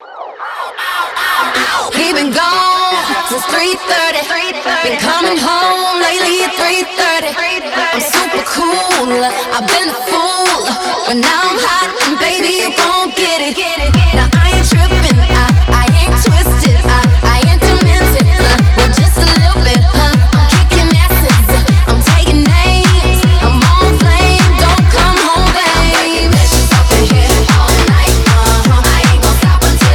Жанр: Танцевальные
Dance